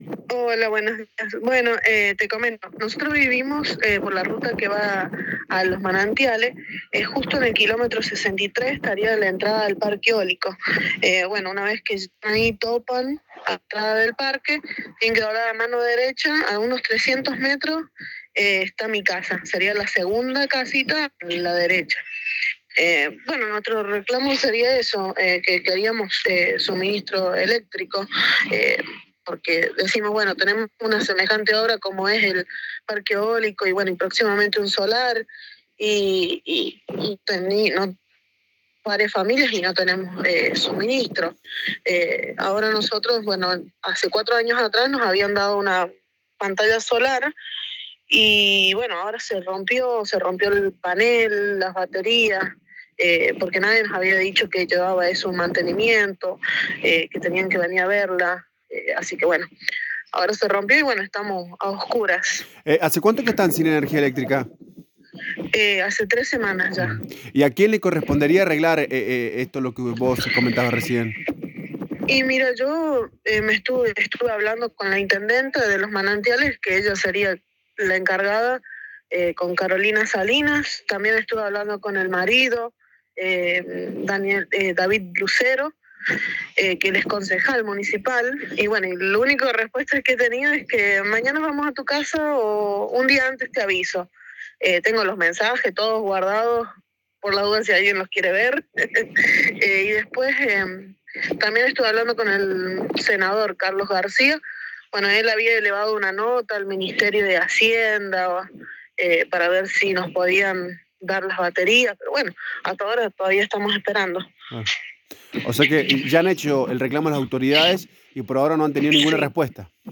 habló en exclusiva con el móvil de Radio La Bomba y expresó la preocupación que tiene la gente que vive en la zona y cuál sería la solución definitiva ya que no sería la primera vez que se quedan sin energía.